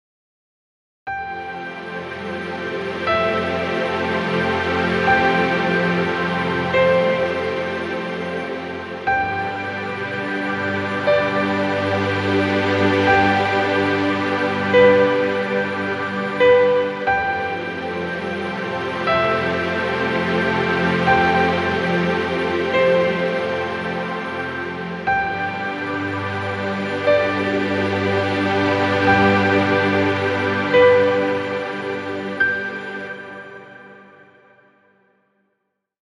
Background Music Royalty Free.